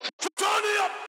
Vox (Waves).wav